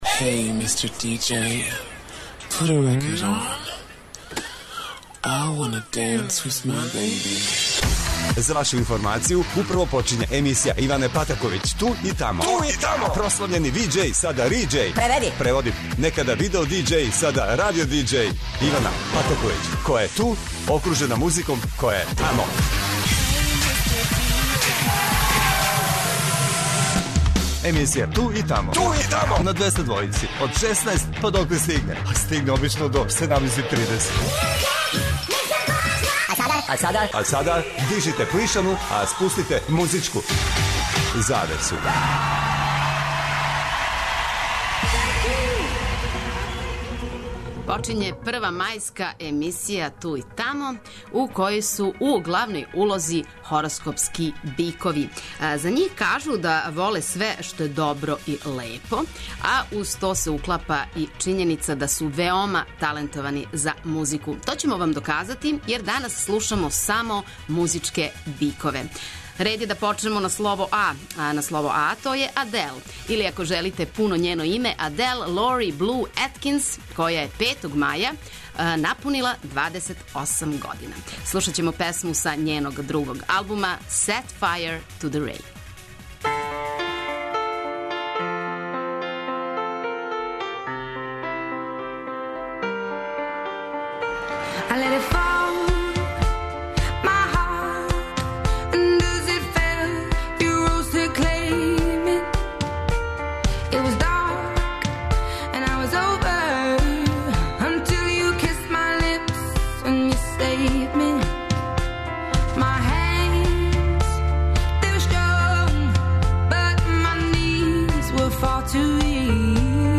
преузми : 42.25 MB Ту и тамо Autor: Београд 202 Емисија Ту и тамо суботом од 16.00 доноси нове, занимљиве и распеване музичке теме. Очекују вас велики хитови, страни и домаћи, стари и нови, супер сарадње, песме из филмова, дуети и још много тога.